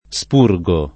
vai all'elenco alfabetico delle voci ingrandisci il carattere 100% rimpicciolisci il carattere stampa invia tramite posta elettronica codividi su Facebook spurgare v.; spurgo [ S p 2 r g o ], -ghi — cfr. espurgare